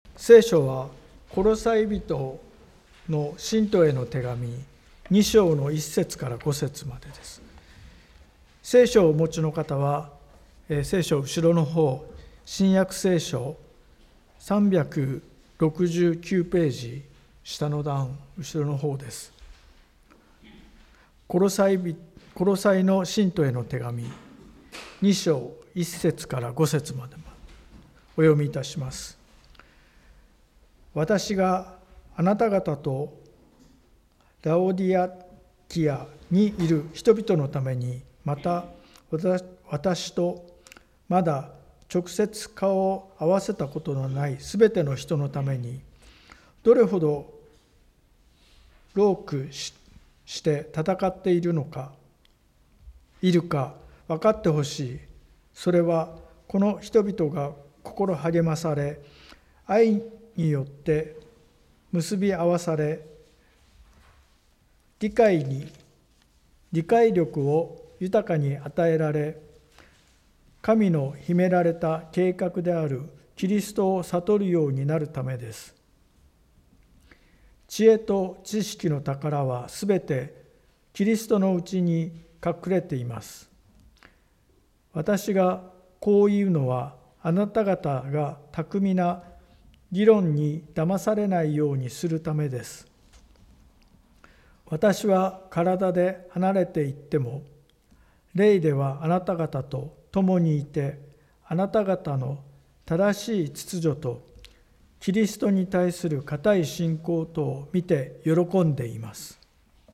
【聖書箇所朗読】